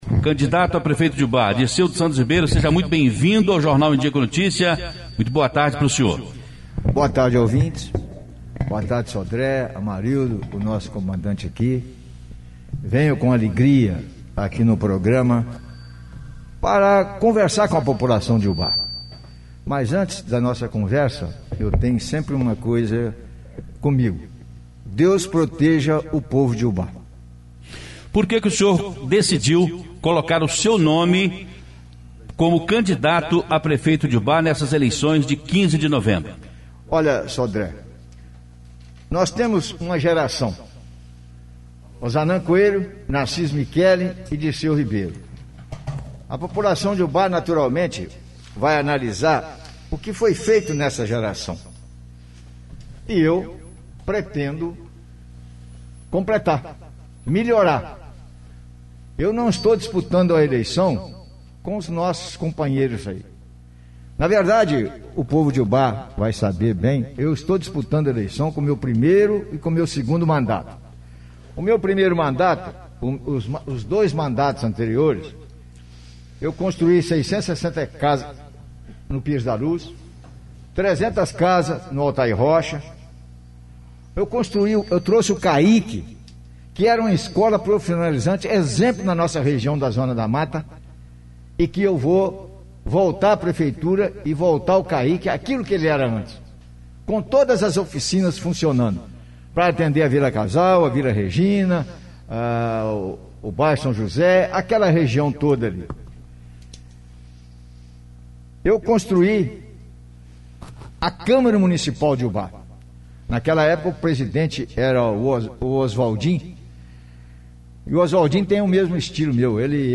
Educadora na boca da urna!Entrevista às 12h30.